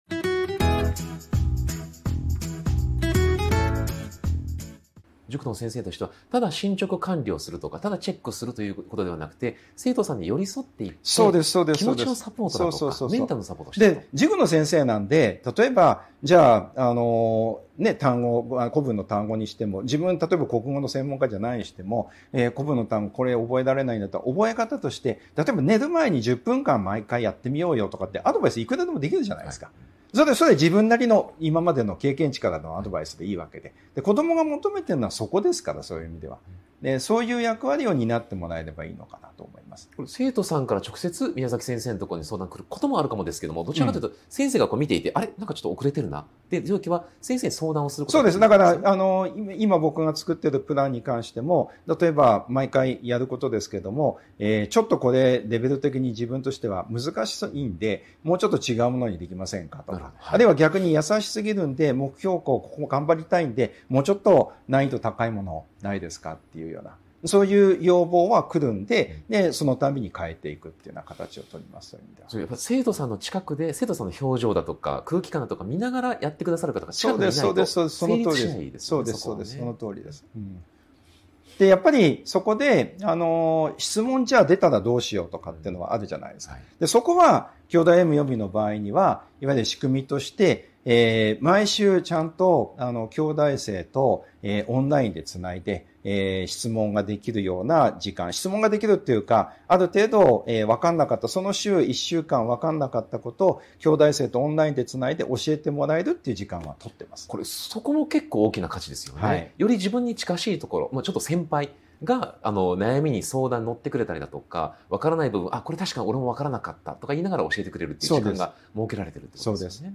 【特別インタビュー】塾業界38年の現場から見える未来の学習塾経営とは 個人塾が生き残るための「縦展開」と「寄り